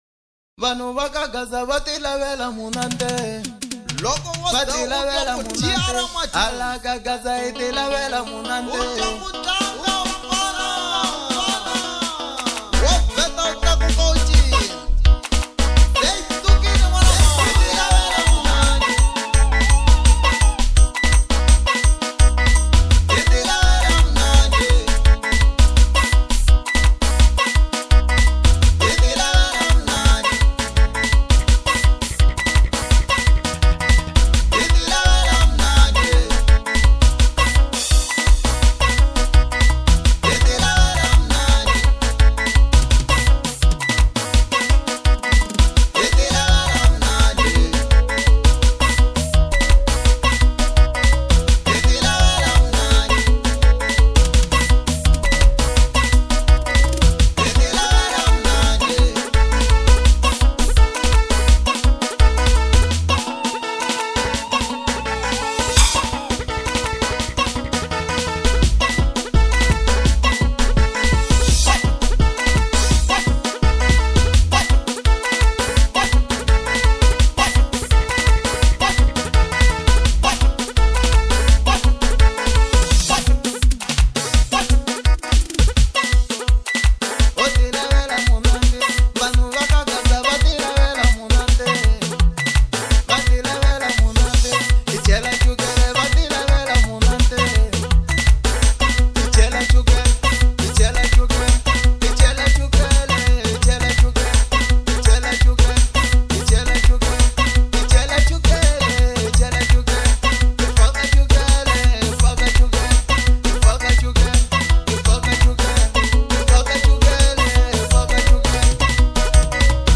04:01 Genre : Xitsonga Size